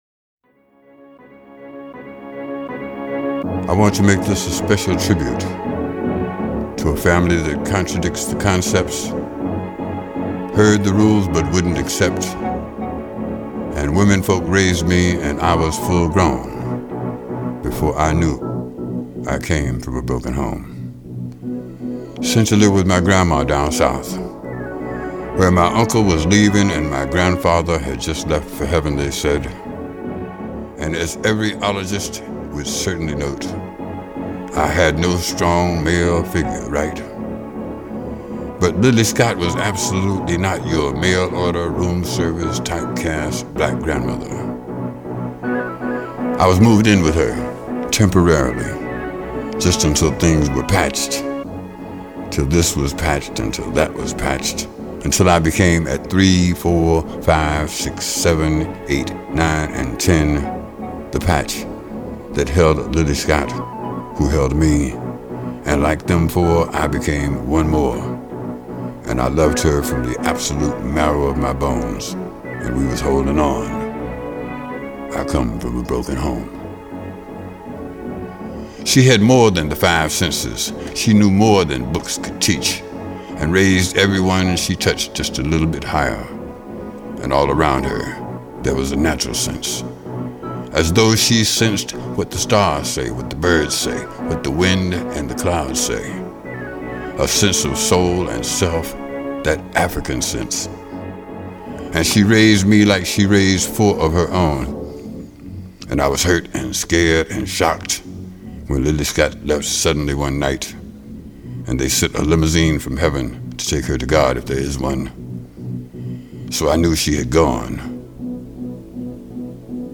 הקטע הזה מגיע בדקלום ספוקן וורד, על רקע של ביטים אפלוליים